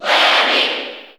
Category: Bowser Jr. (SSBU) Category: Crowd cheers (SSBU) You cannot overwrite this file.
Lemmy_Cheer_English_SSB4_SSBU.ogg